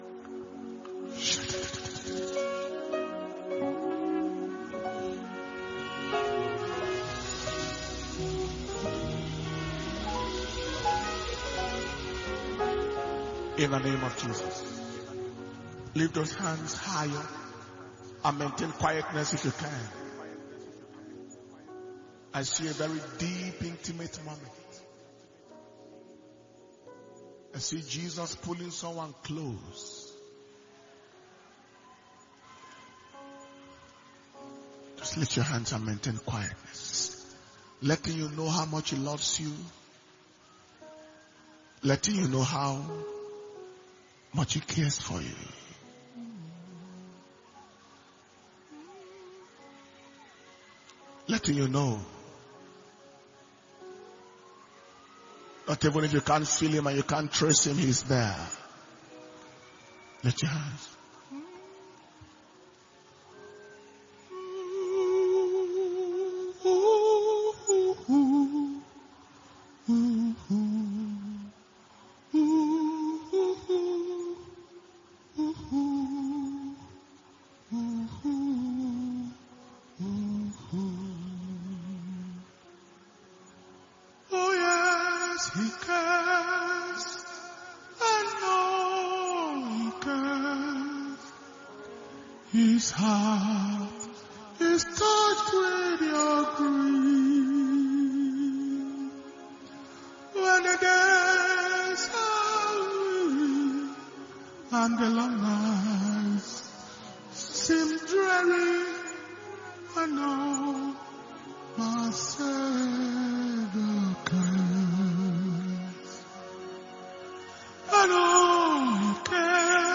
June 2023 Impartation Service – Sunday 18th, June 2023.